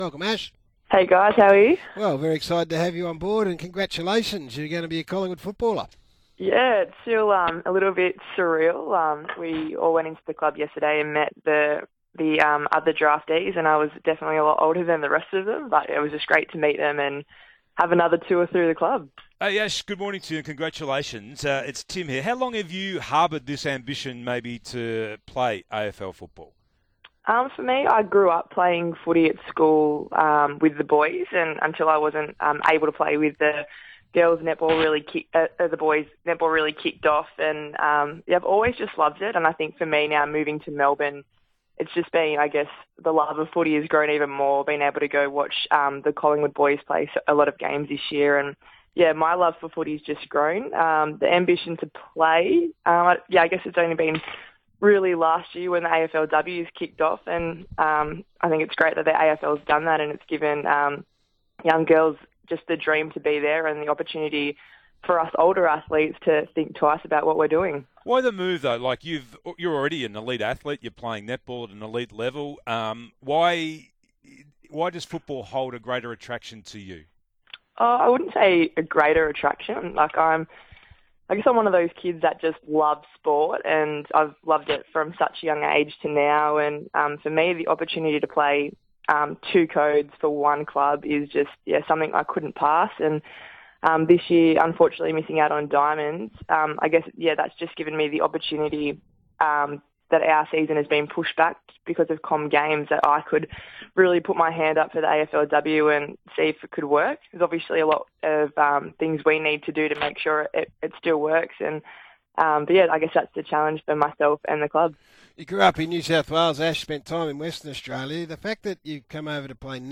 Collingwood all-rounder Ash Brazill spoke to SEN Breakfast on Thursday 19 October after being drafted to the Pies' AFLW team.